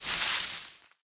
gamedata / sounds / material / human / step / bush04gr.ogg
bush04gr.ogg